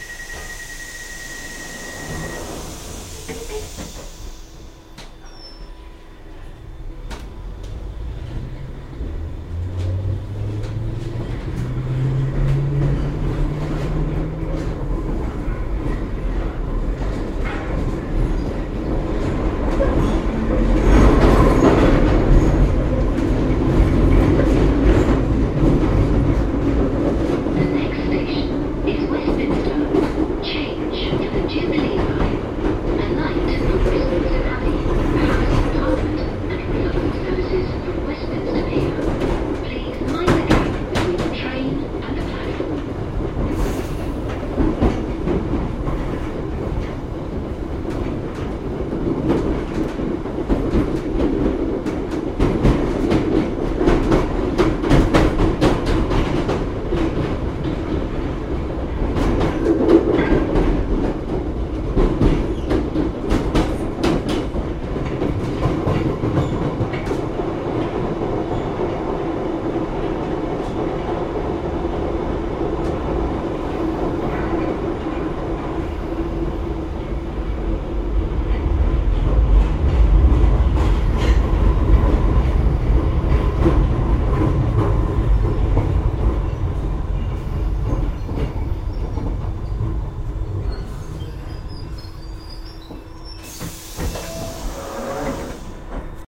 这是列车在车站前刹车的情况。
Tag: 到达 制动 现场记录 伦敦 地铁 车站 列车 地下